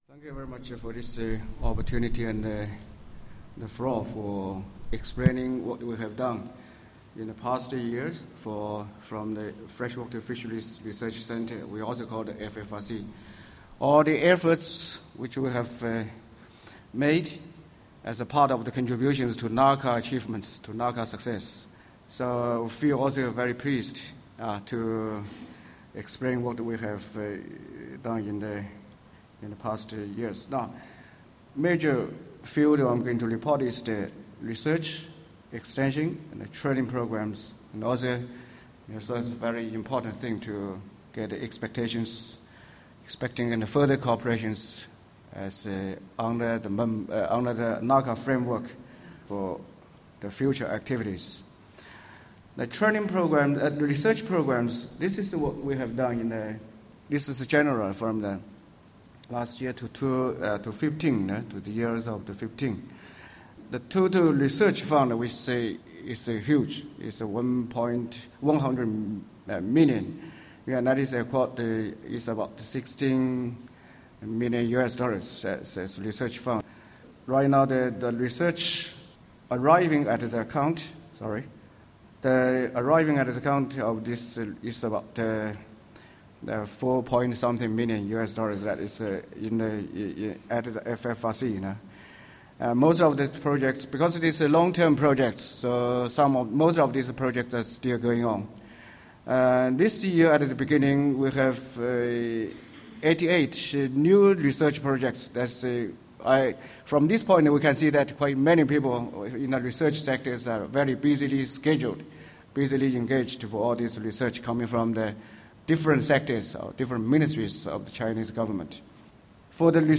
Related 2012 progress reports of the NACA Regional Lead Centres The recordings in this collection are the annual progress reports of NACA's regional lead centres in Thailand, China and the Philippines. The presentations were made at the 23rd NACA Governing Council Meeting, which was held in Siem Reap, 27-29 May 2011, hosted by the Government of Cambodia.